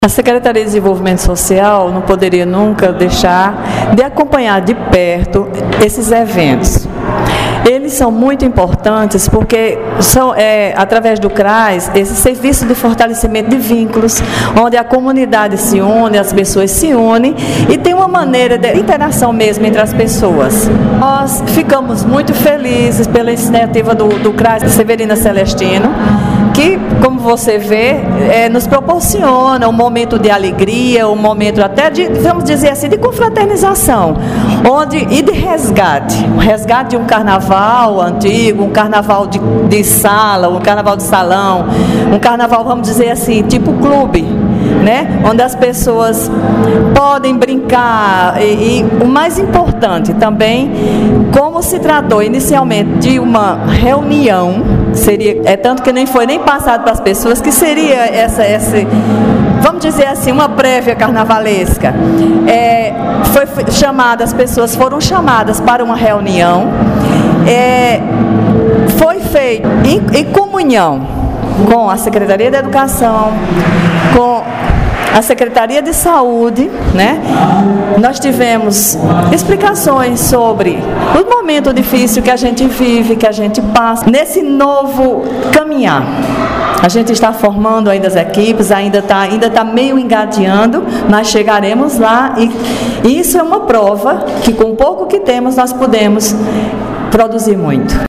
Fala da secretária de Desenvolvimento Social, Verônica Leite –